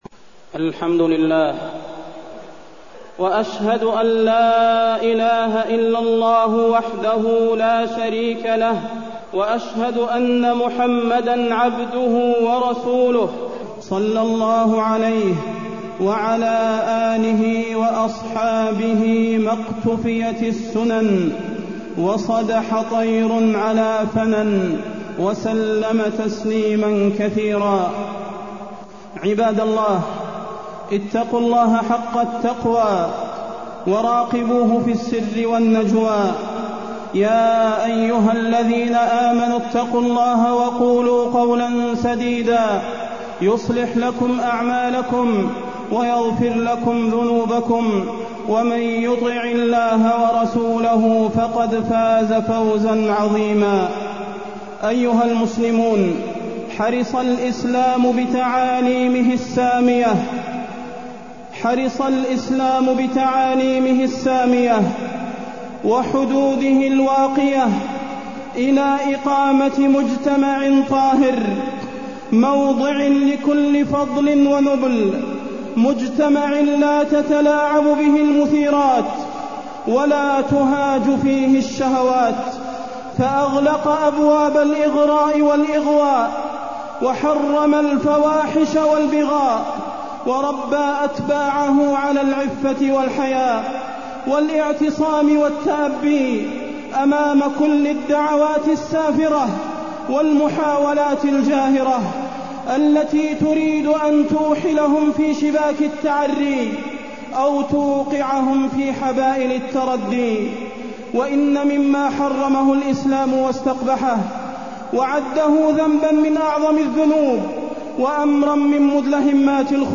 تاريخ النشر ٢٦ ربيع الثاني ١٤٢١ هـ المكان: المسجد النبوي الشيخ: فضيلة الشيخ د. صلاح بن محمد البدير فضيلة الشيخ د. صلاح بن محمد البدير فاحشة الزنا وآثارها